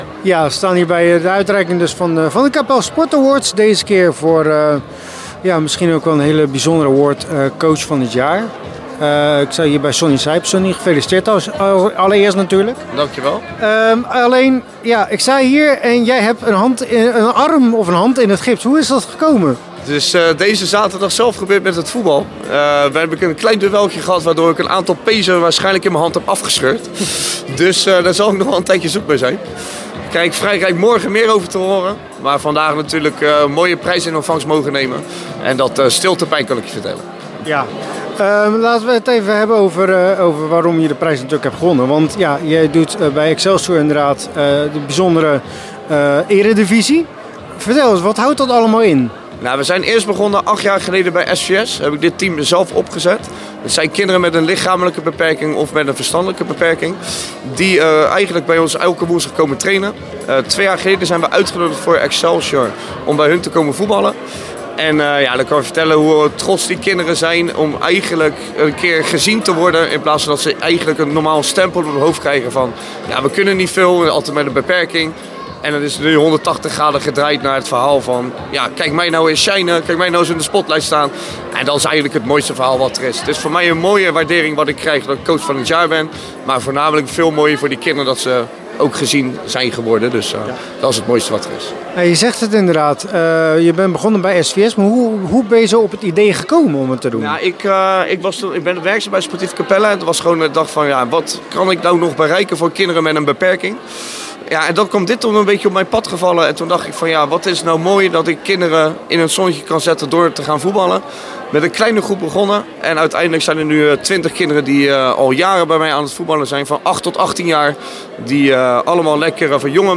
In deze podcast een interview met hem.